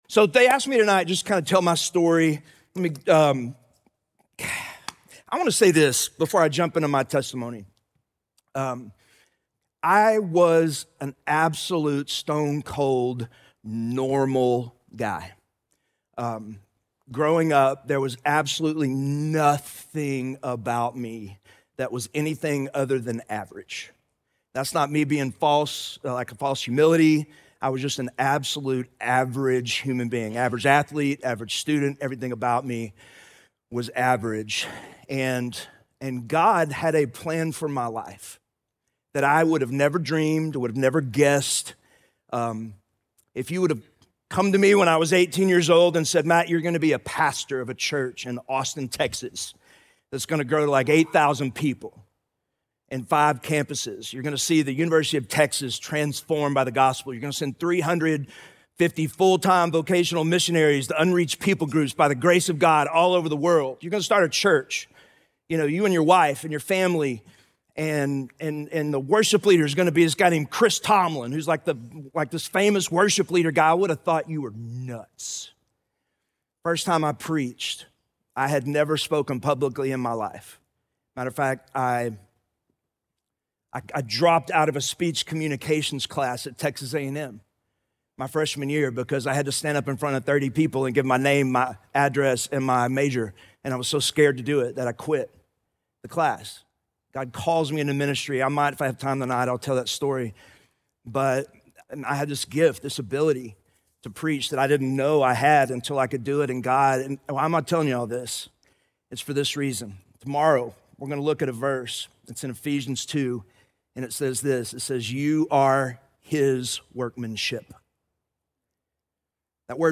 2026 Main Session #1